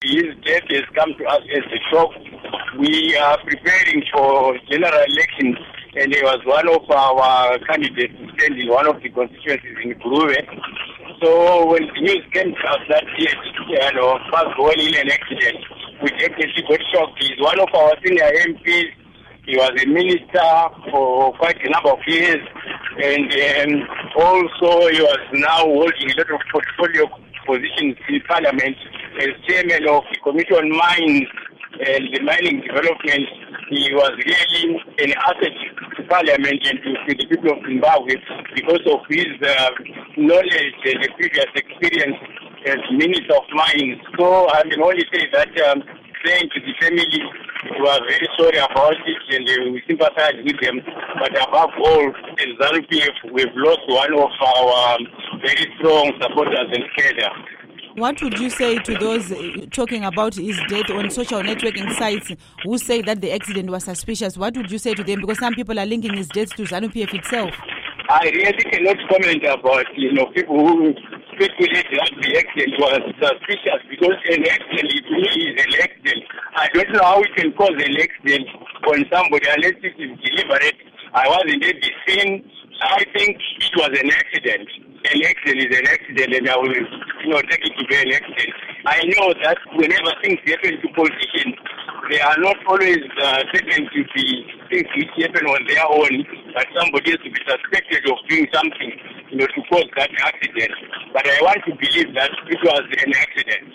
Interview With Joram Gumbo